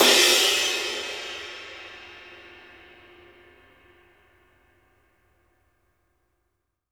DARKCRASH -R.wav